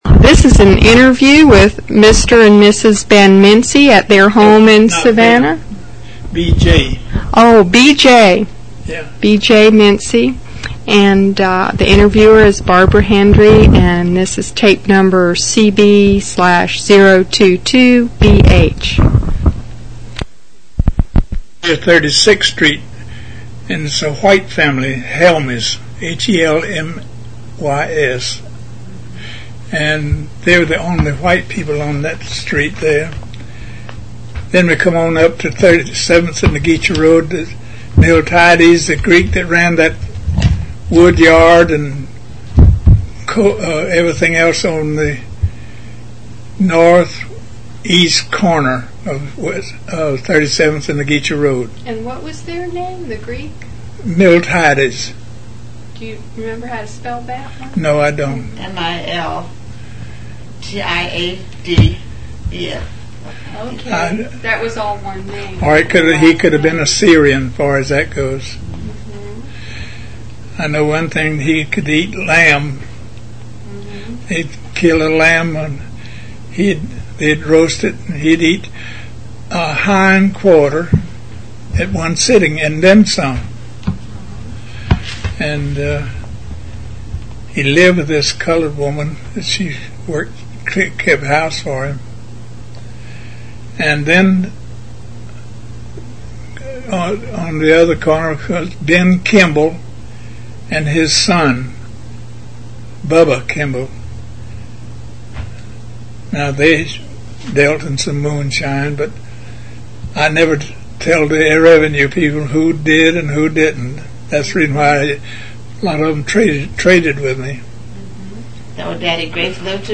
Object Name Tape, Magentic Credit line Courtesy of City of Savannah Municipal Archives Copyright Requests to publish must be submitted in writing to Municipal Archives.